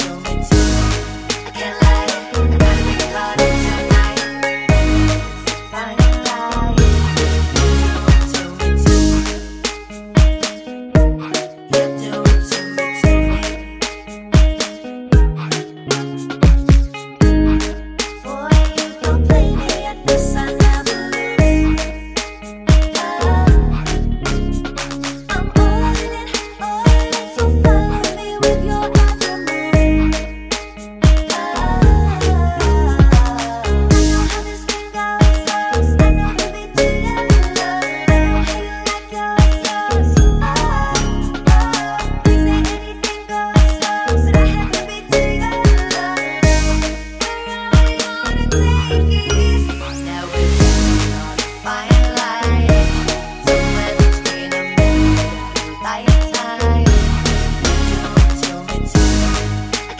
no Backing Vocals R'n'B / Hip Hop 3:32 Buy £1.50